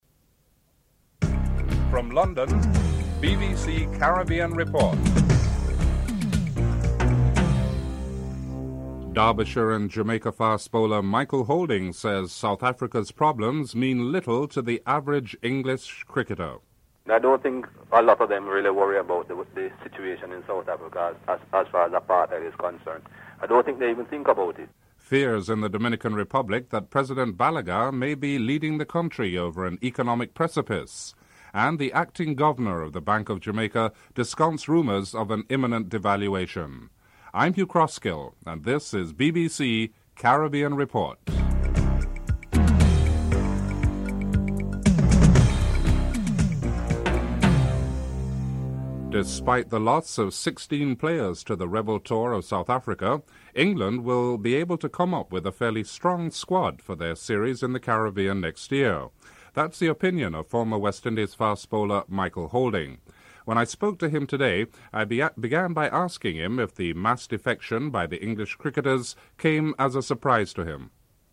1. Headlines (00:48-01:28)
3. Financial news (06:05-07:40)